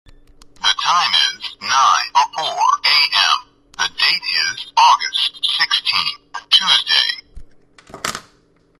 • Features clear, easy-to-hear male voice.
talking_keychain_calendar.mp3